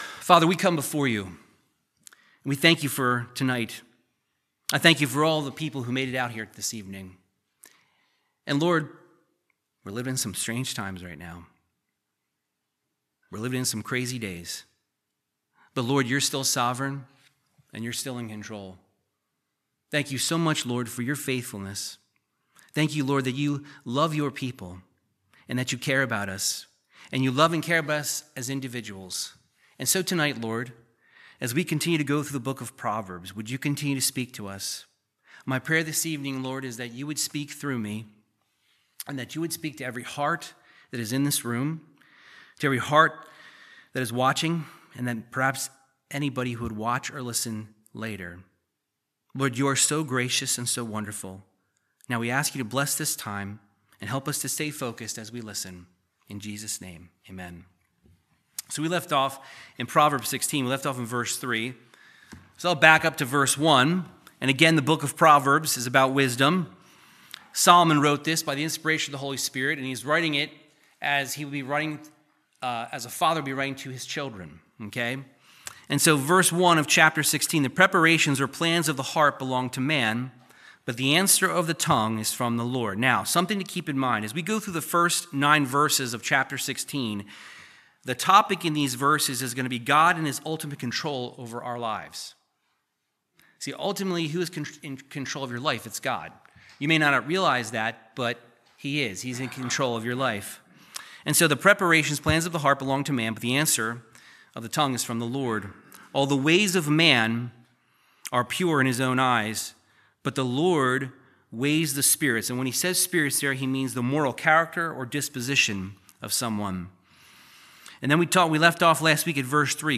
Verse by verse Bible teaching of the wisdom of King Solomon from Proverbs 16:4 through 17:19